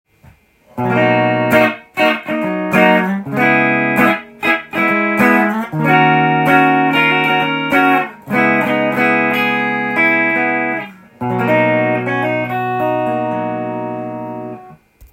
続いてハムバッカーでコード弾き
ハムバッカータイプは、太くてしっかりした音です。